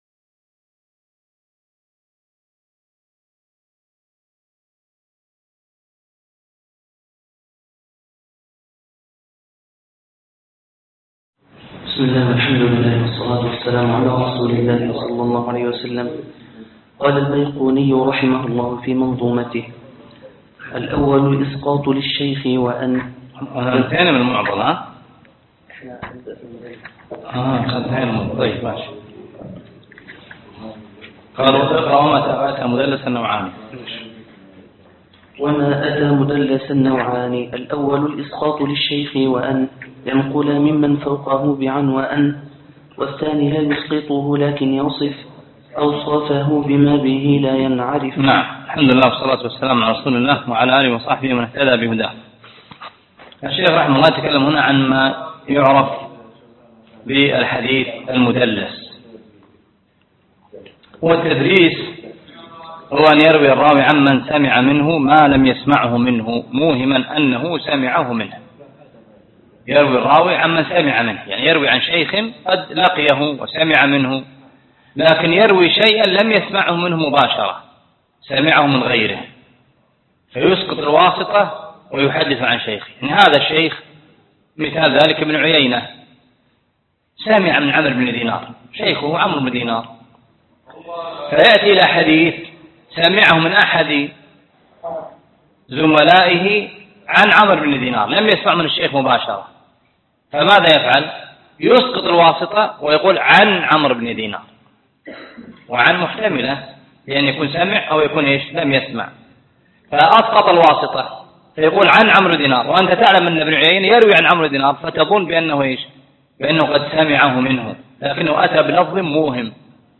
الدرس الثانى ( شرح المنظومة البيقونية)